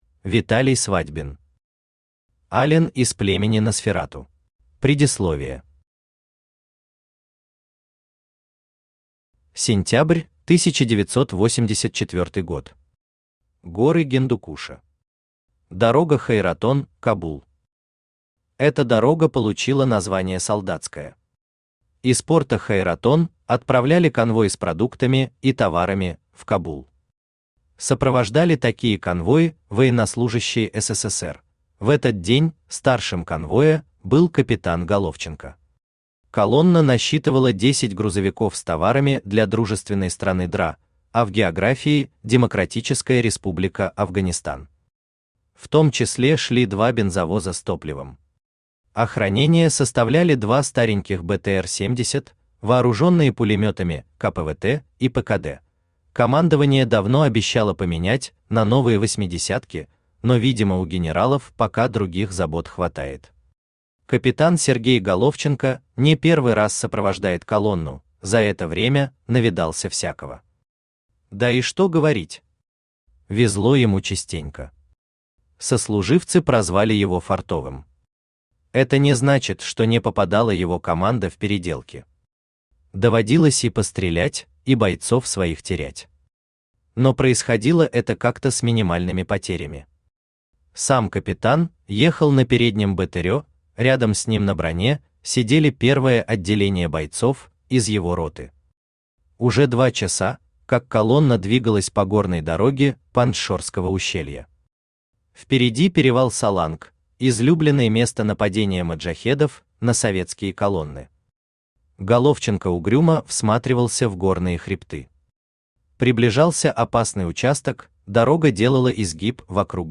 Аудиокнига Ален из племени Носферату | Библиотека аудиокниг
Aудиокнига Ален из племени Носферату Автор Виталий Свадьбин Читает аудиокнигу Авточтец ЛитРес.